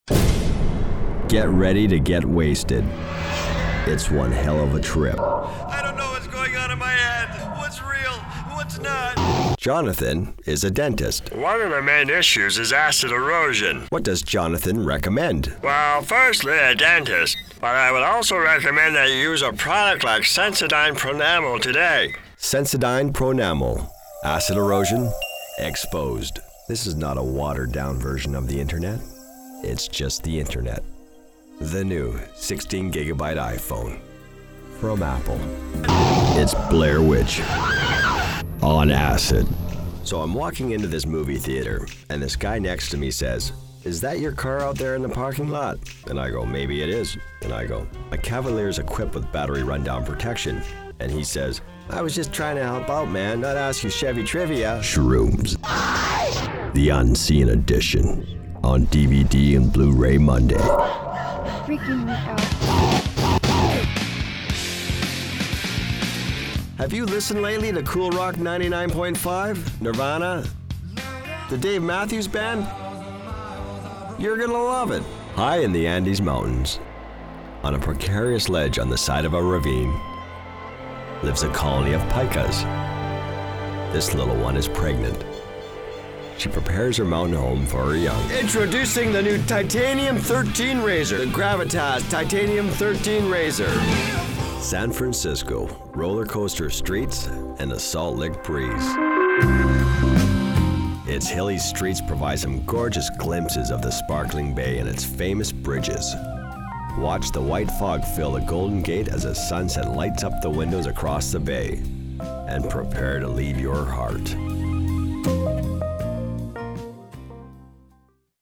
North American deep voice, warm serious
Sprechprobe: Industrie (Muttersprache):
Movie trailer voice to warm and mysterious